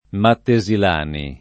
vai all'elenco alfabetico delle voci ingrandisci il carattere 100% rimpicciolisci il carattere stampa invia tramite posta elettronica codividi su Facebook Mattesilani [ matte @ il # ni ] o Mattesillani [ matte @ ill # ni ] cogn.